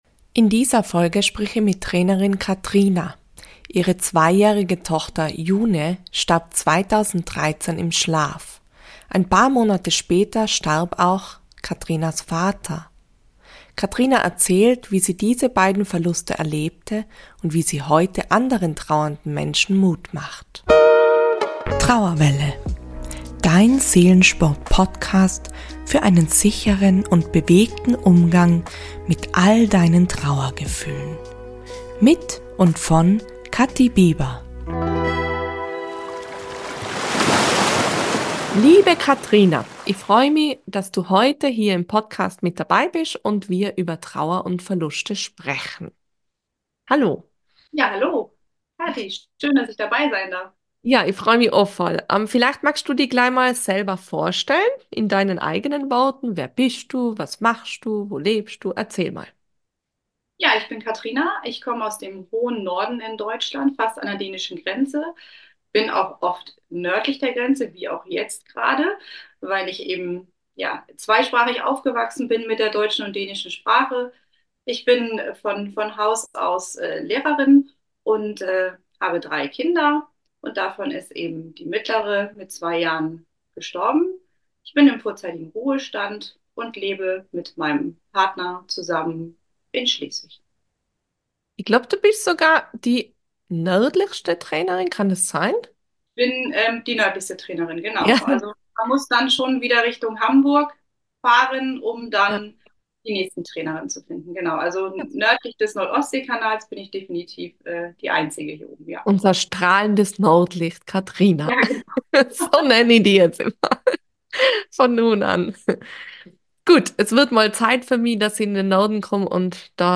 Interview ~ Trauerwelle Podcast